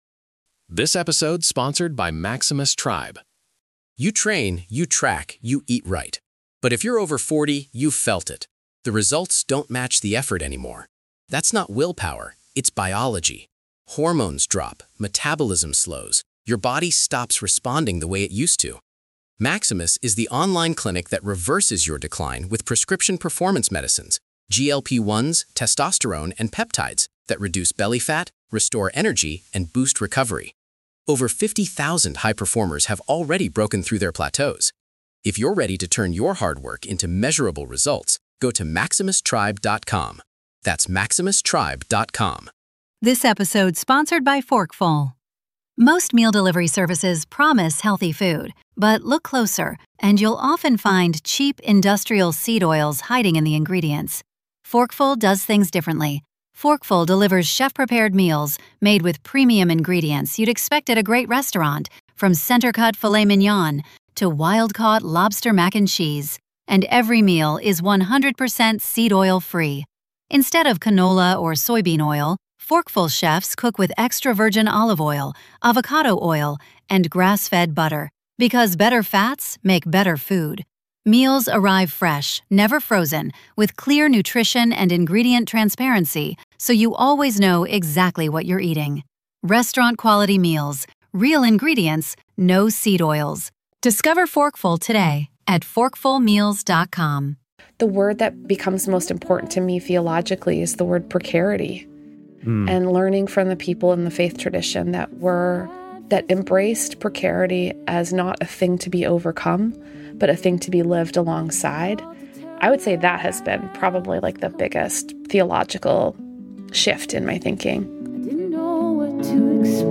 Guest Info/Bio: This week I welcome the incredible Kate Bowler, PhD!